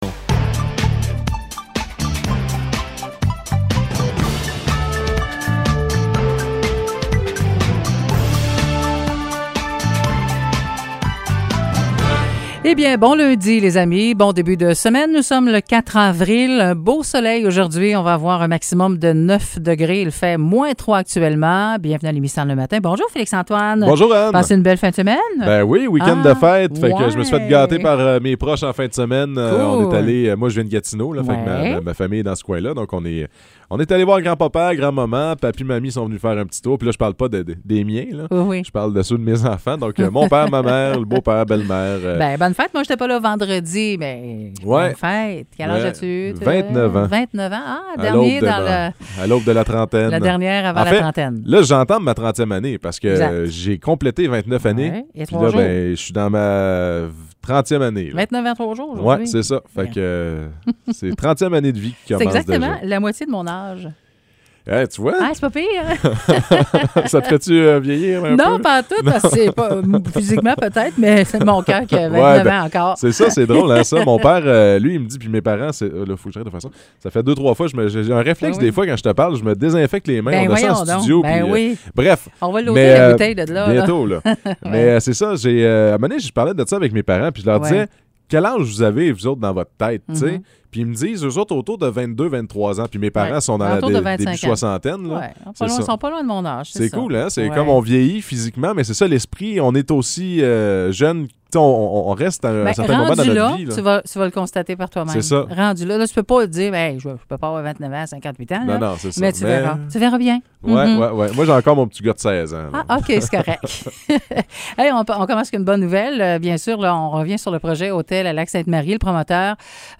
Nouvelles locales - 4 avril 2022 - 9 h